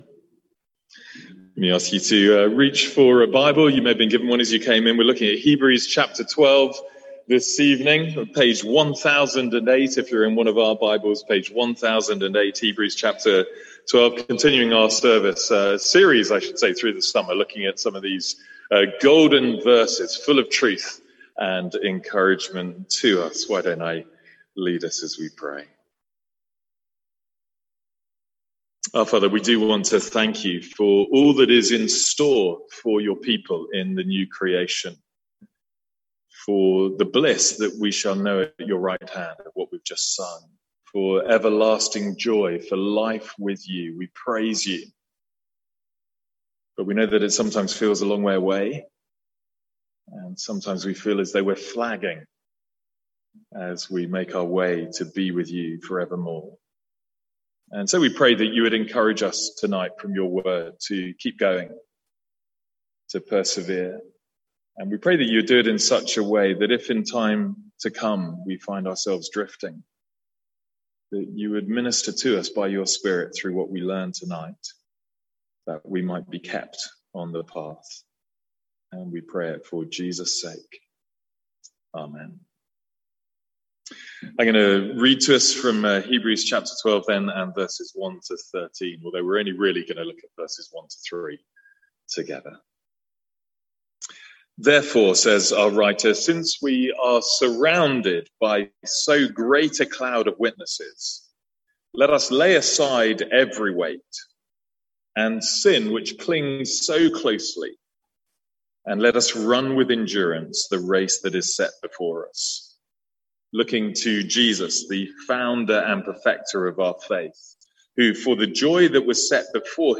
Sermons | St Andrews Free Church
From our evening series in "Calendar Verses"